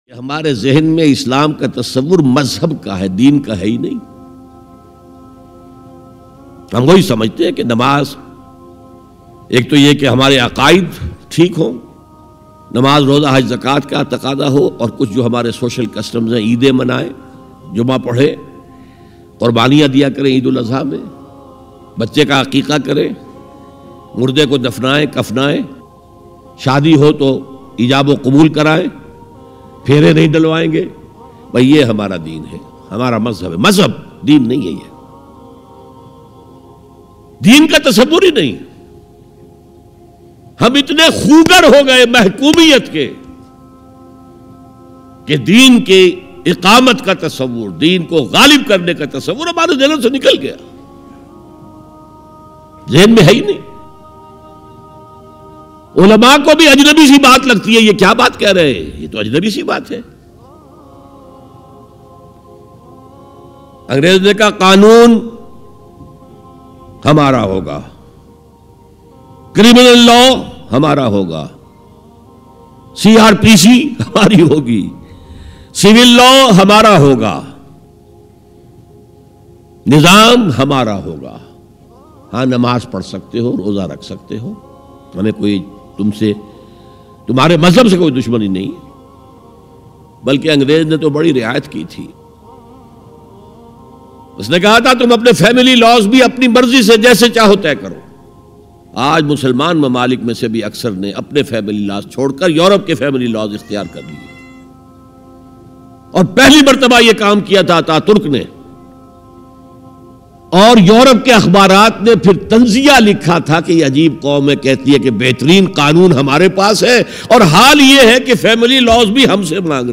Deen Kya Hai Dr Israr Ahmed Latest Bayan MP3 Download